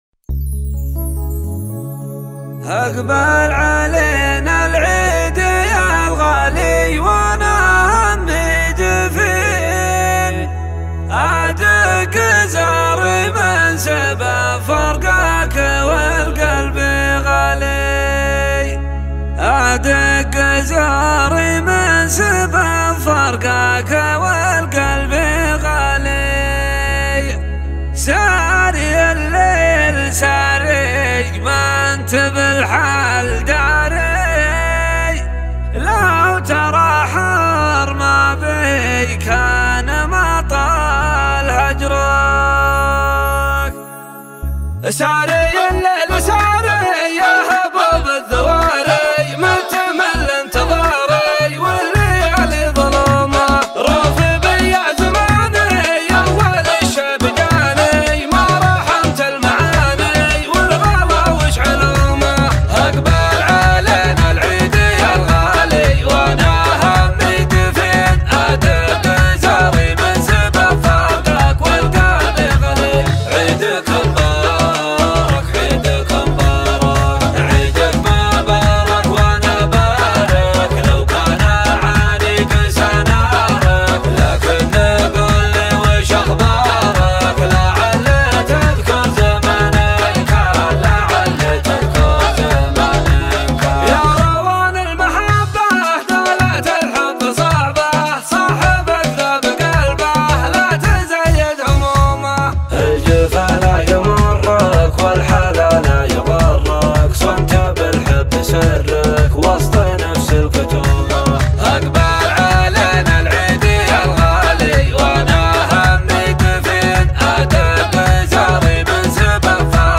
شيلات عيدية